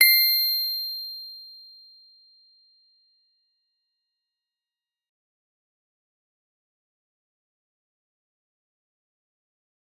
G_Musicbox-C7-f.wav